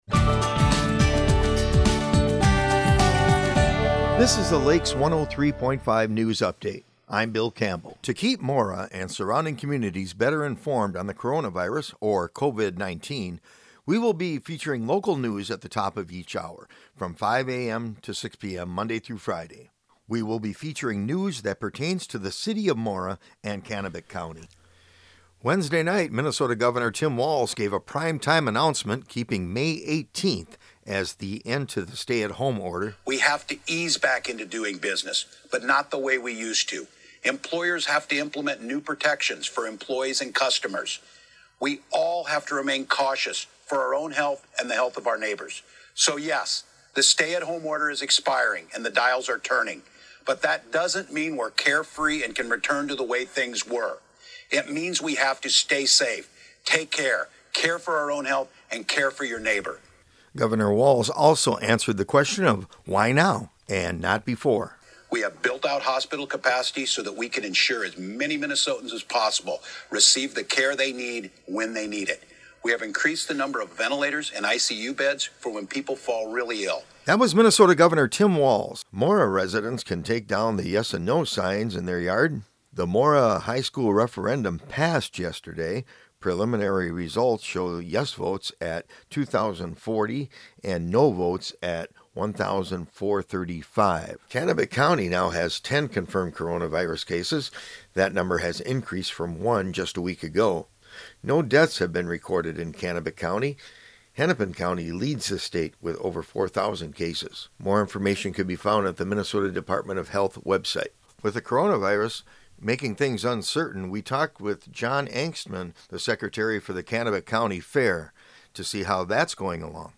This is an archived recording of a feature originally broadcast on Lakes 103.